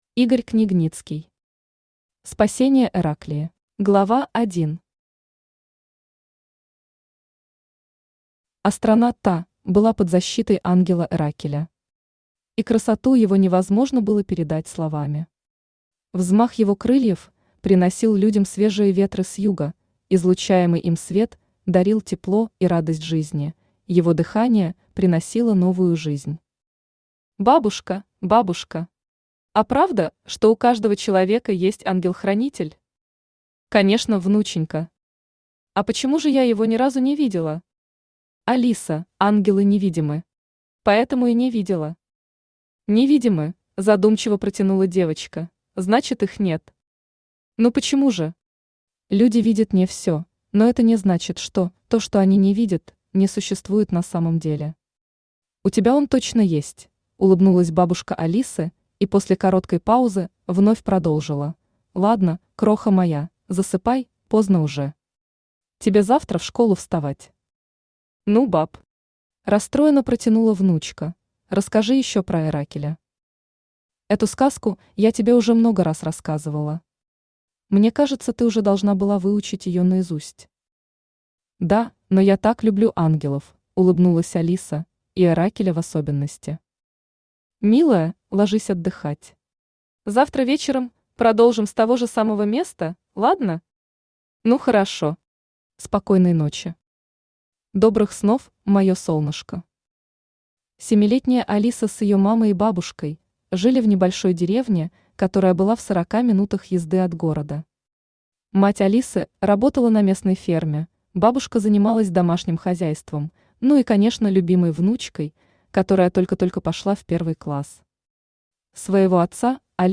Аудиокнига Спасение Эраклии | Библиотека аудиокниг
Aудиокнига Спасение Эраклии Автор Игорь Владимирович Княгницкий Читает аудиокнигу Авточтец ЛитРес.